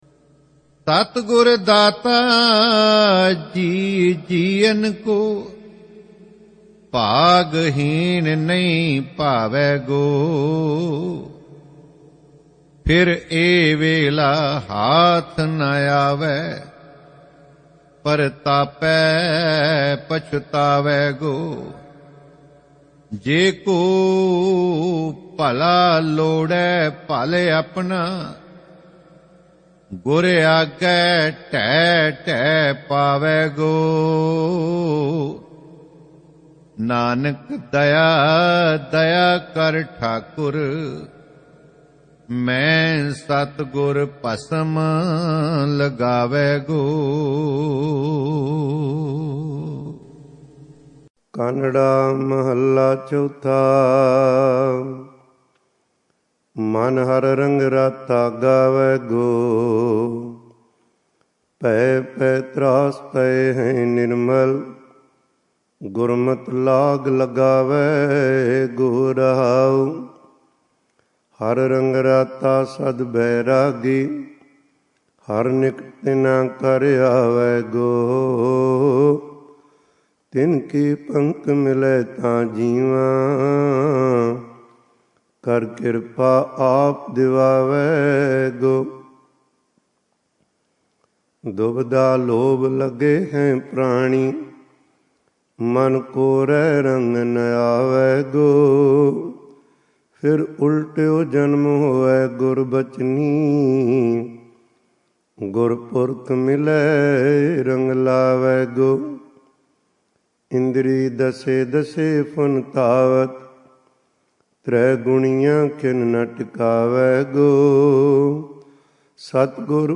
Sehaj Paath Ang-1310 add
SGGS Sehaj Paath